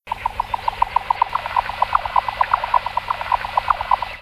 Bécassine sourde, lymnocryptes minimus